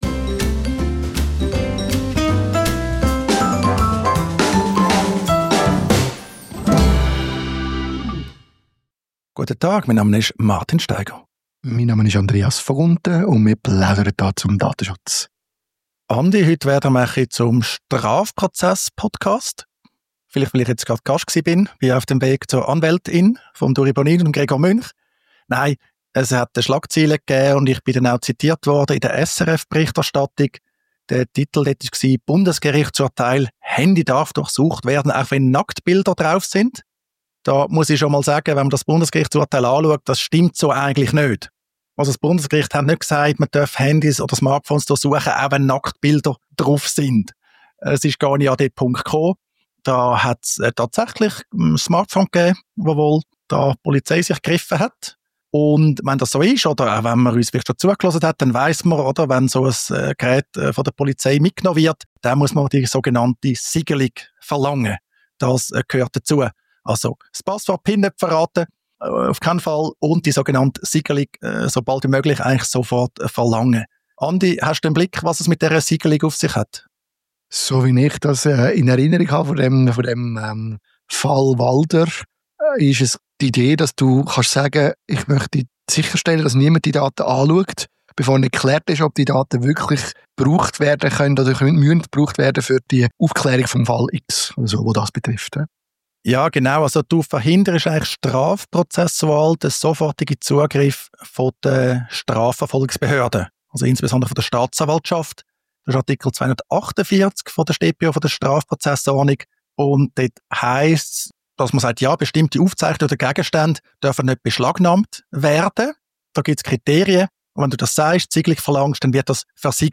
diskutieren ein neues Bundesgerichtsurteil, das die Hürden für die Siegelung von Daten auf einem beschlagnahmten Smartphone erhöht. Was muss man über das Urteil wissen und wieso ist der direkte Schutz der Daten letztlich wichtiger als die Möglichkeit zur Siegelung?